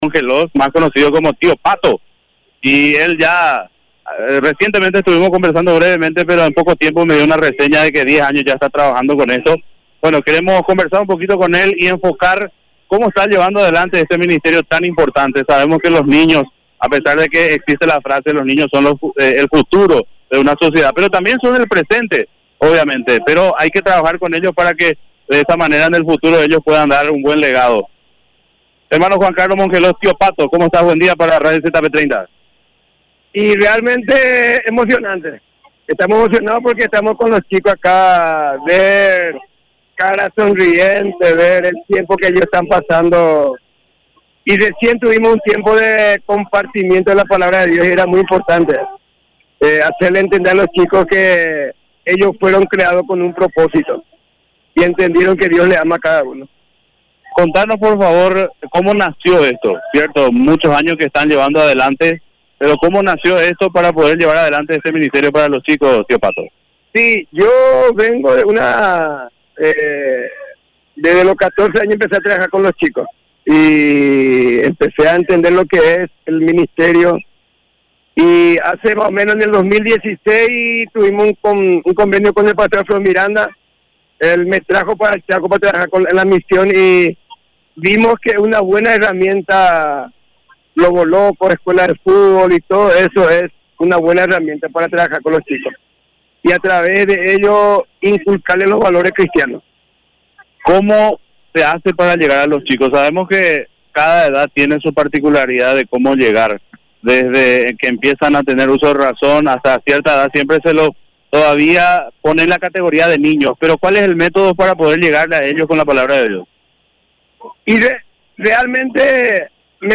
Entrevistas / Matinal 610 Trabajos de evangelización a los niños Aug 16 2024 | 00:10:16 1x 00:00 / 00:10:16 Subscribe Share RSS Feed Share Link Embed